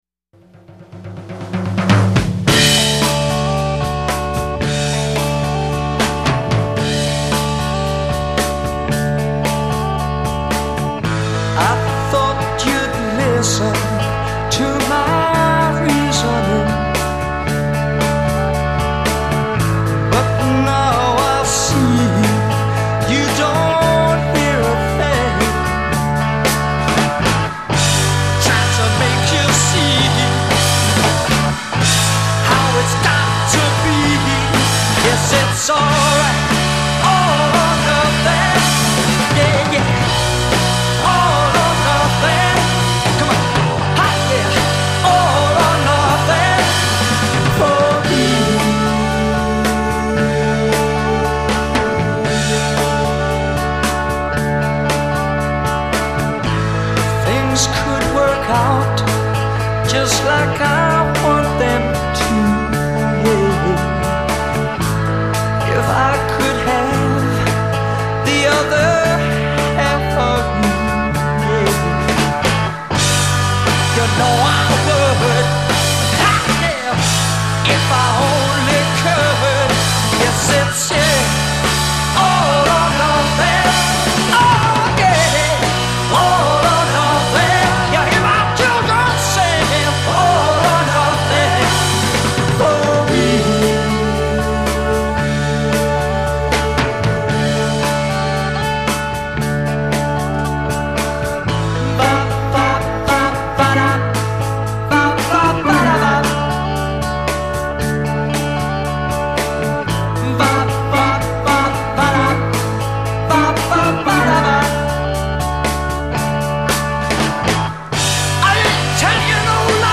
Recorded at IBC Studios, Portland Place, London; June 1966.
A1 Vrs p1 :   wordless vocal/organ solo first half
B coda :   return to intro material